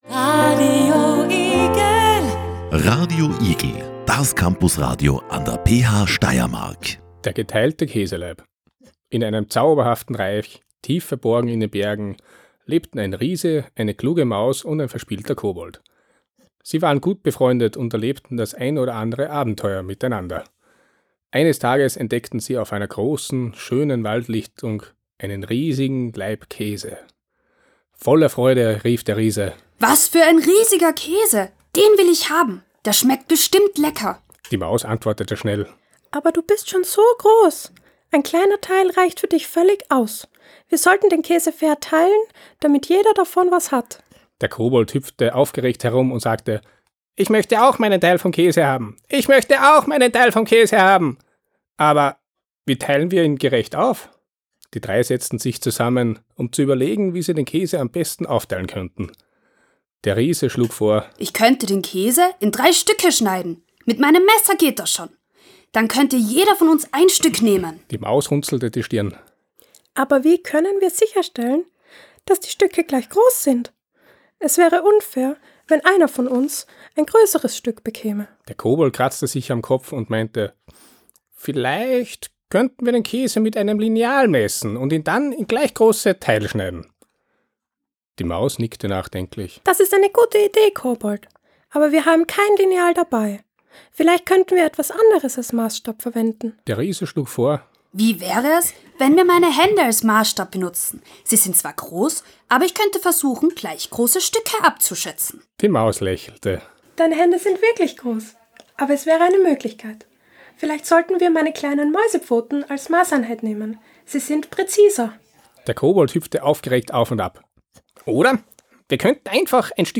Der geteilte Käselaib - ein Hörspiel über Brüche